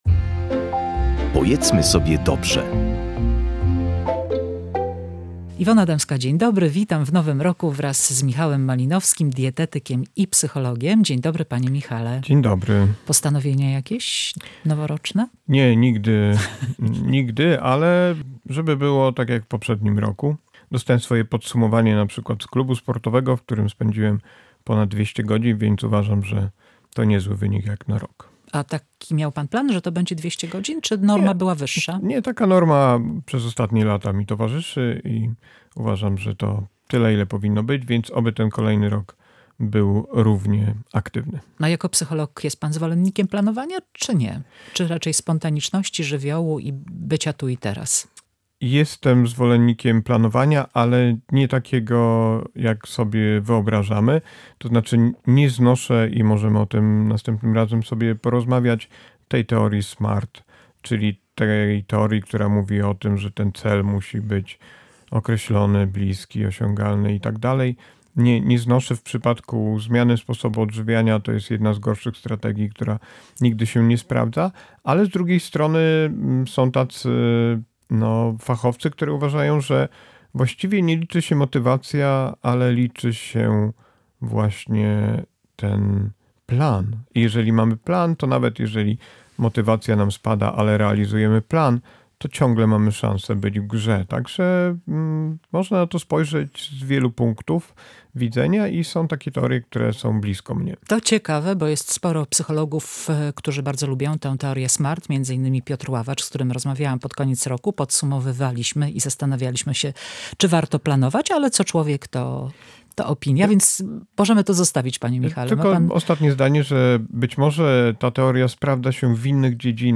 Na temat mikrobiomu i mikrobioty – dietetycznych słów minionego roku – rozmawiali w audycji „Pojedzmy sobie dobrze”